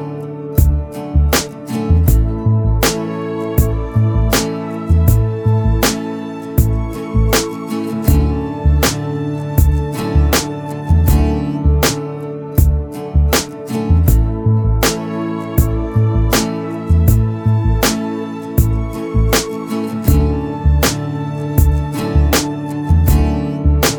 Minus Guitars Pop (2000s) 4:28 Buy £1.50